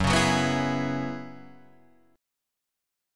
Listen to F#9sus4 strummed